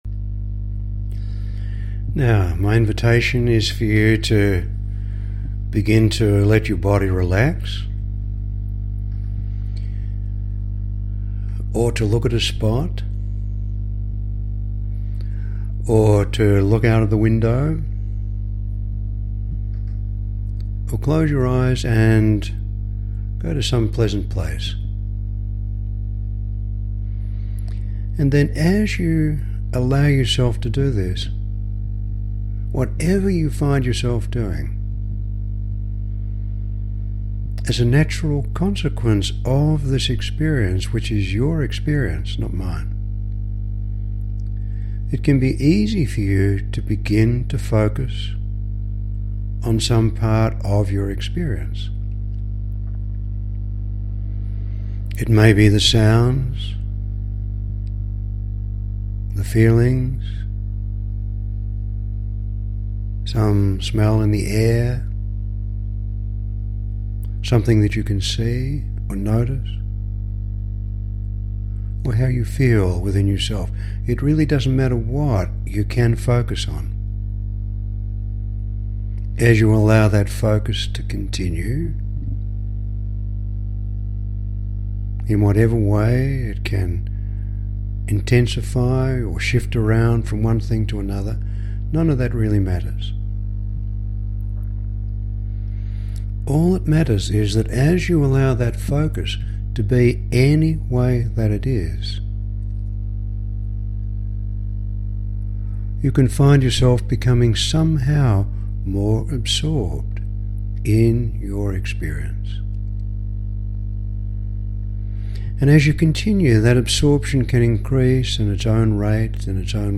a generic invitation into hypnosis - an example
A very relaxing experience.
Very natural and normal.